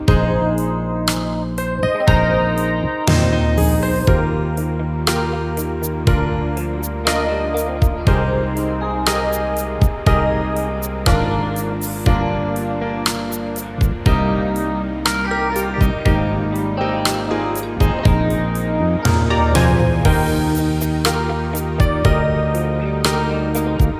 For Solo Singer Soul / Motown 4:10 Buy £1.50